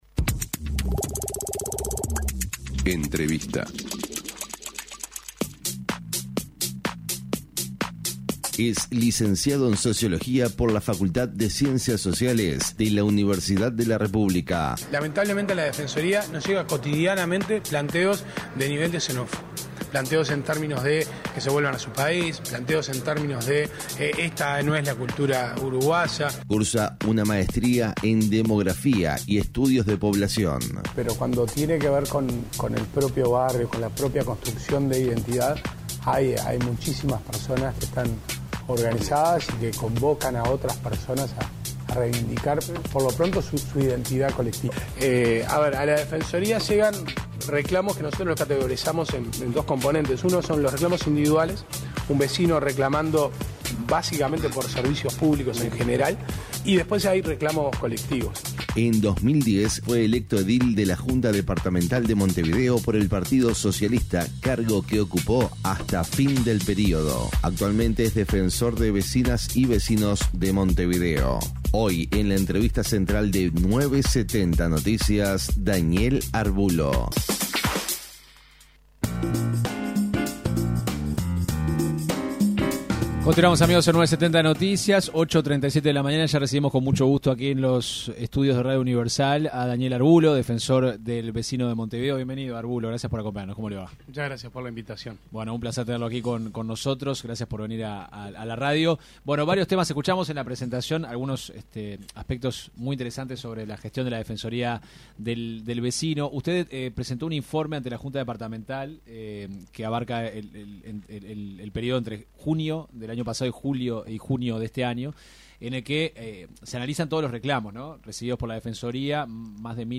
El titular de la Defensoría del Vecino y la Vecina de Montevideo, Daniel Arbulo, se refirió en una entrevista con 970 Noticias, a las personas en situación de calle y las quejas que se reciben por parte de la sociedad.